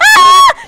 ooff3.wav